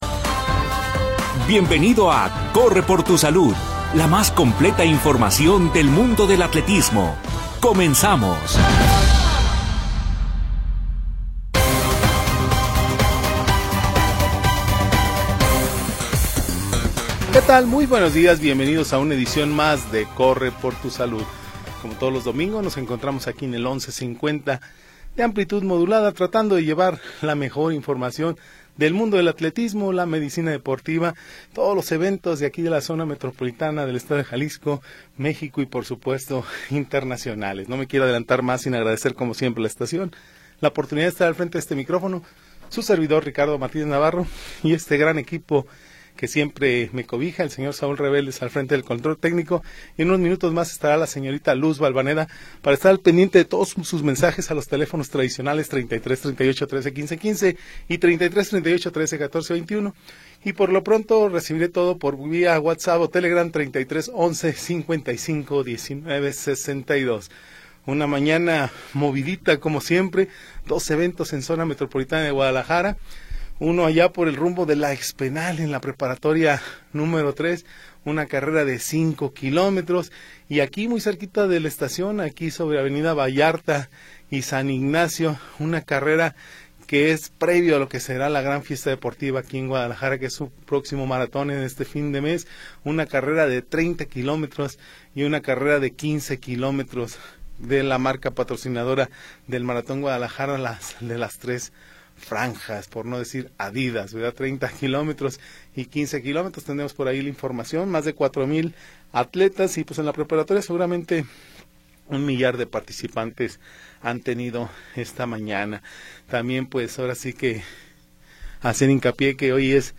Atletismo, nutrición, ejercicio sin edad. Bajo la conducción del equipo de deportes Notisistema.